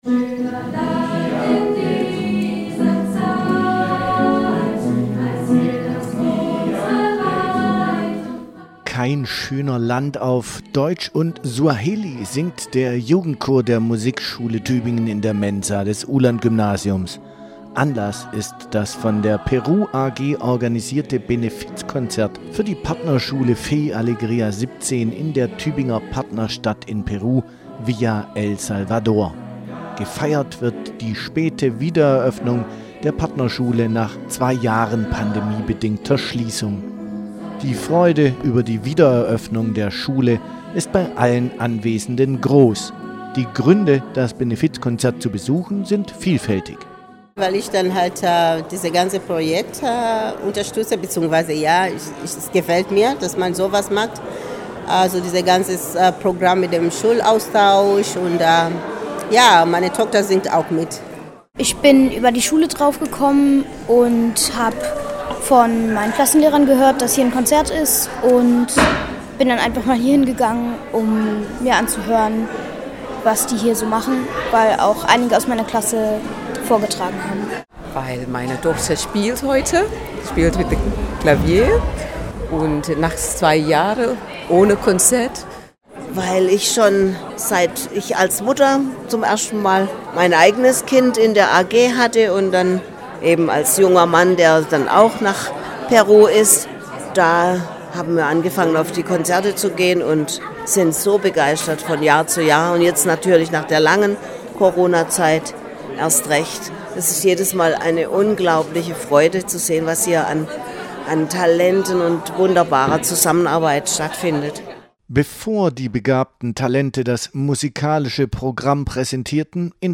Beim Benefizkonzert für die Partnerschule des Uhlandgymnasiums in Peru, Fe y Alegria 17 in der Tübinger Partnerstadt Villa El Salvador feierte die Peru AG mit den zahlreichen Besucher*innen die späte Wiederöffnung der Schule nanch einer fast zweijährigen Pause.
Das bunte musikalische Programm der vielen jungen Talente begeisterte das Publikum mit Darbietungen von Chorgesang bis zu Pianosolos.